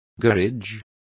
Also find out how taller is pronounced correctly.